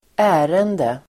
Uttal: [²'ä:rende]